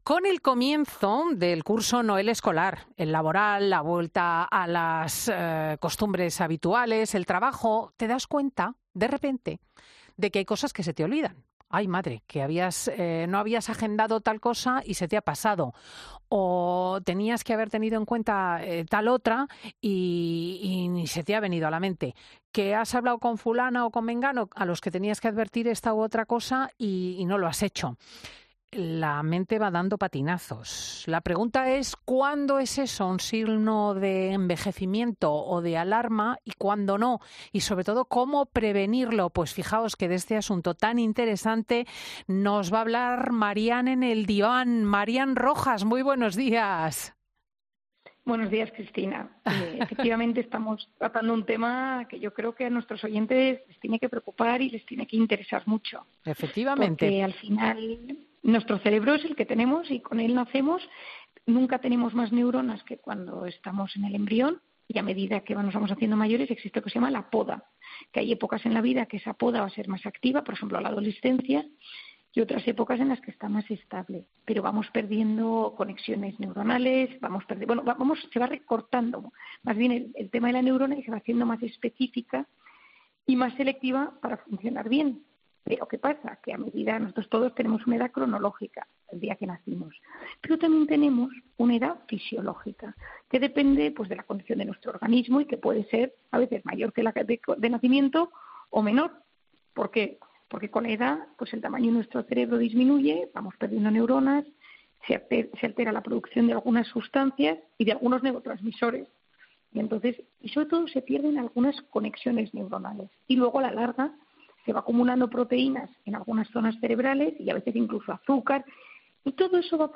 AUDIO: La psiquiatra de cabecera de Fin de Semana da las claves para prevenir las consecuencias del envejecimiento cerebral